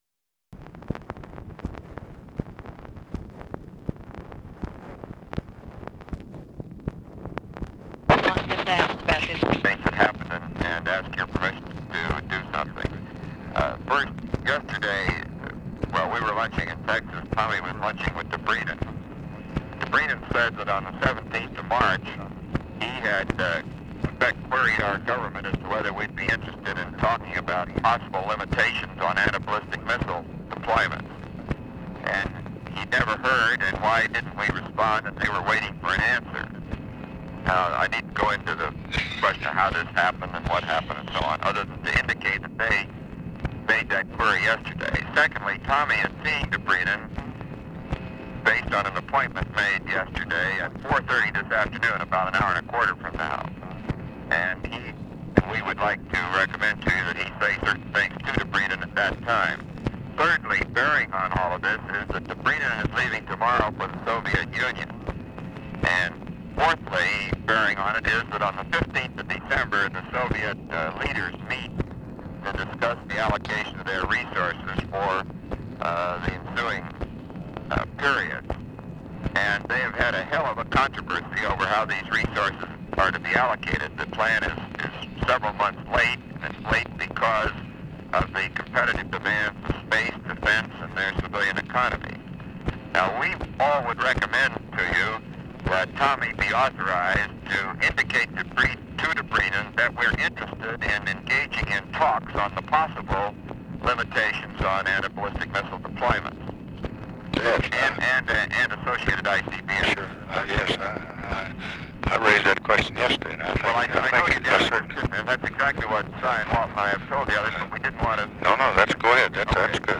Conversation with ROBERT MCNAMARA, December 7, 1966
Secret White House Tapes